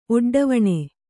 ♪ oḍḍavaṇe